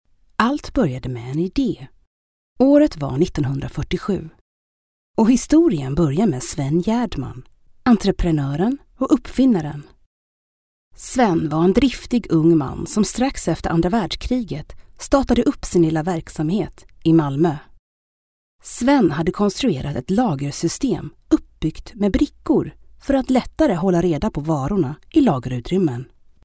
Swedish female voice over